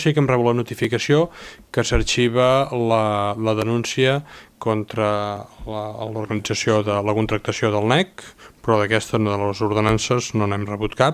L’alcalde Marc Buch, que s’havia mostrat “extremadament tranquil” i confiat que la denúncia quedaria en un no-res, informava dijous passat de la resolució d’Antifrau.